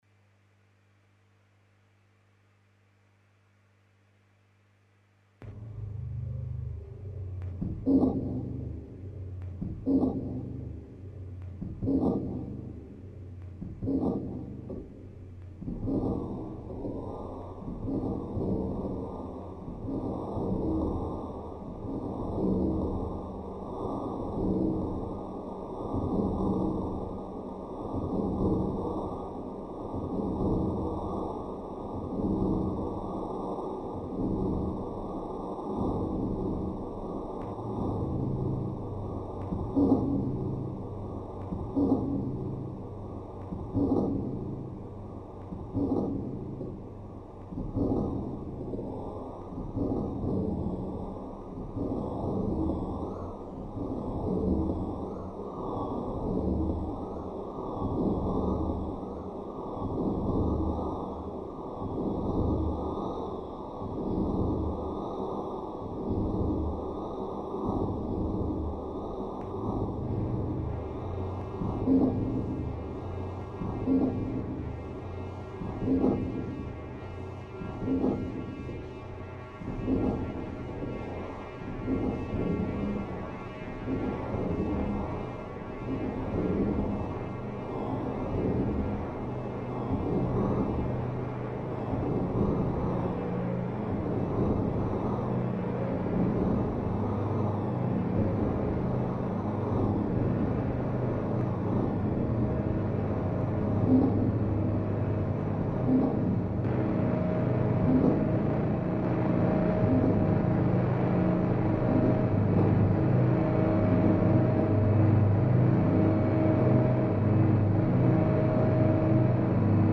cinq platines vinyle jouant simultanément cinq interprétations différentes
Installation